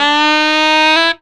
COOL SAX 3.wav